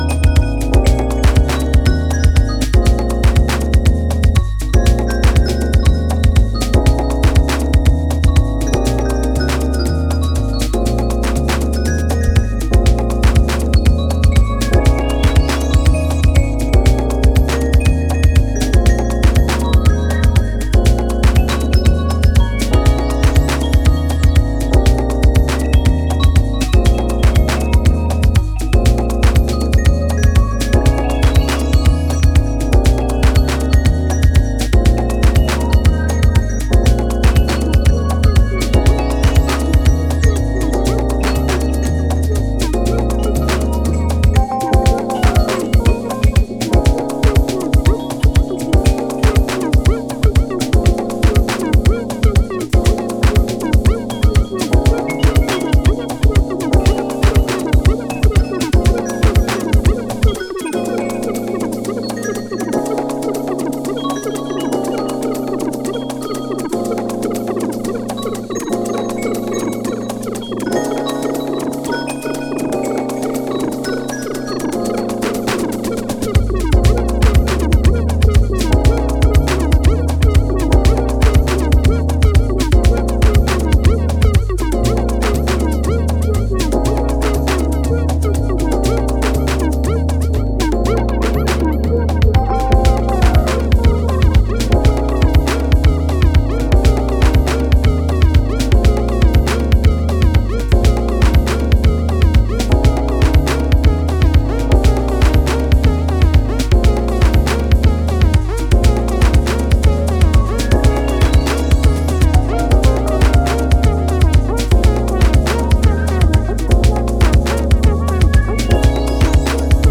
Styl: Disco, House, Breaks/Breakbeat